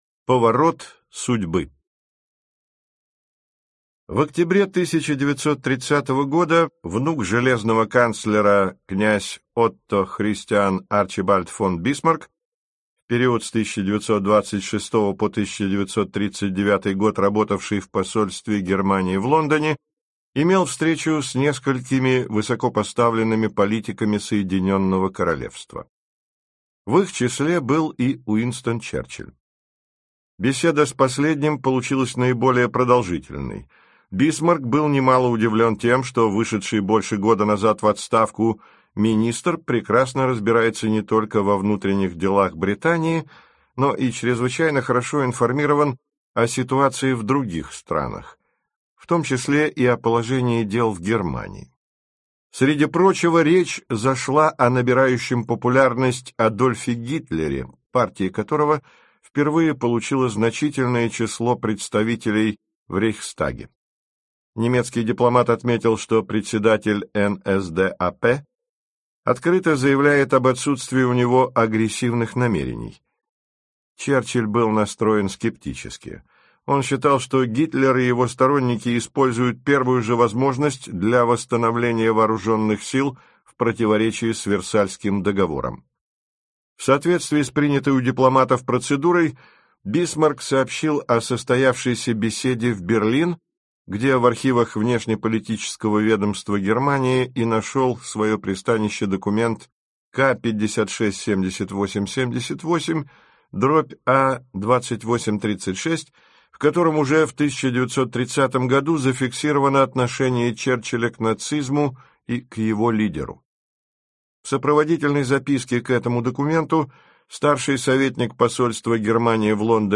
Аудиокнига Черчилль. Против течения. Часть 5 | Библиотека аудиокниг